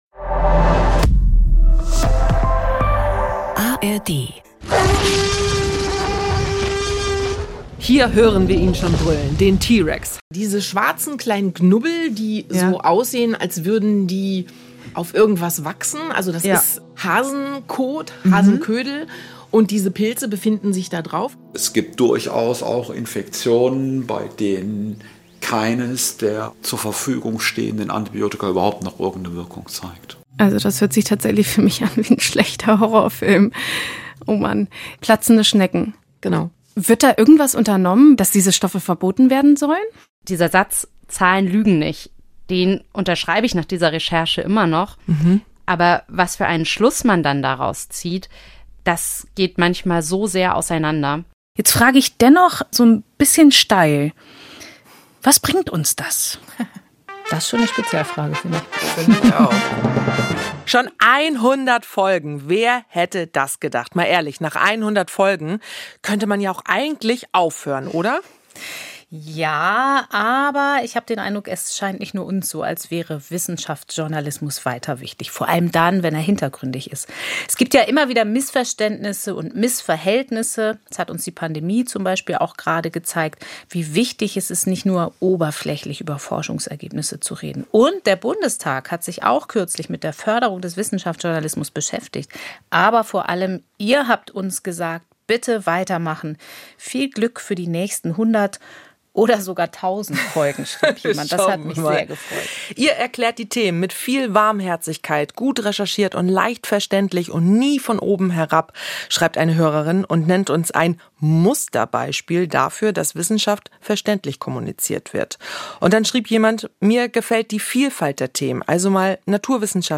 Dabei kommen auch die Hörerinnen und Hörer per E-Mail zu Wort.
Außerdem äußern sich auch viele prominente Forscherinnen und Forscher dazu, wie wichtig das transparente Arbeiten in der Wissensvermittlung durch die Medien gerade in gesellschaftlichen Diskussionen ist, um Vertrauen in wissenschaftliche Arbeit und auch wissenschaftlich basierte Entscheidungen zu stärken.